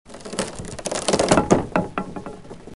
Oggetto legno rotolante
Suono rumore oggetto di legno tagliato che rotola.